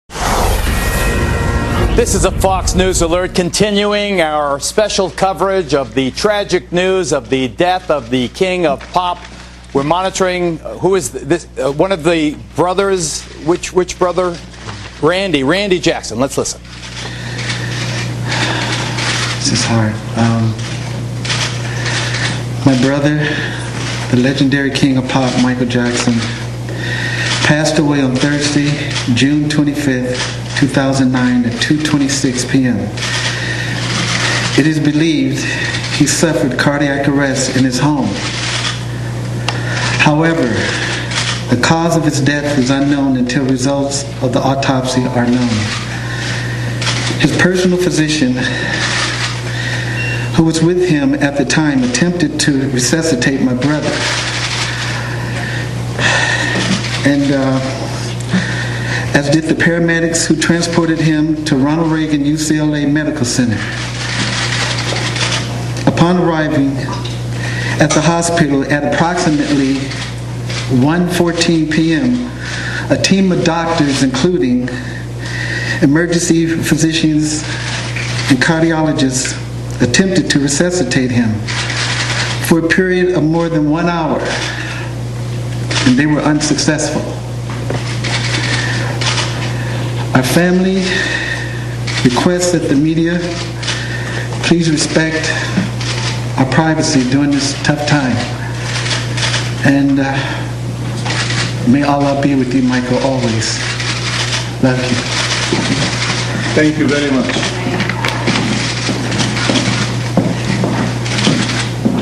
Jermaine Jackson gives a public statement on his brother Michael’s death.